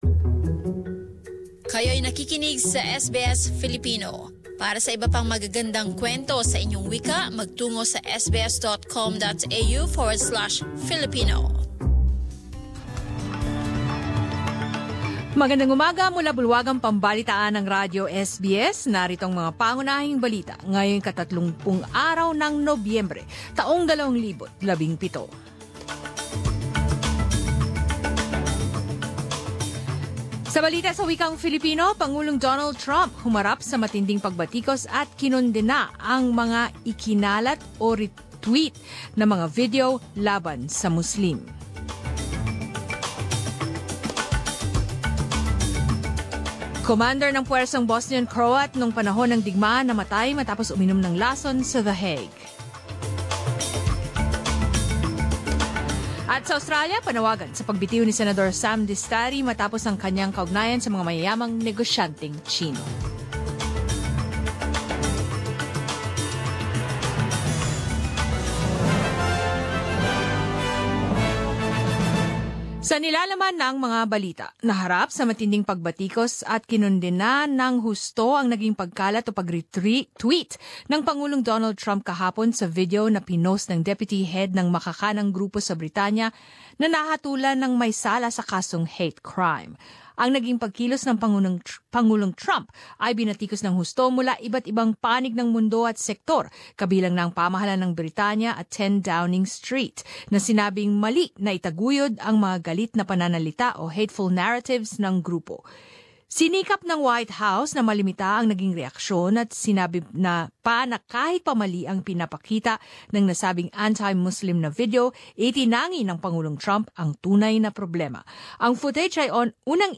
10 am News Bulletin in Filipino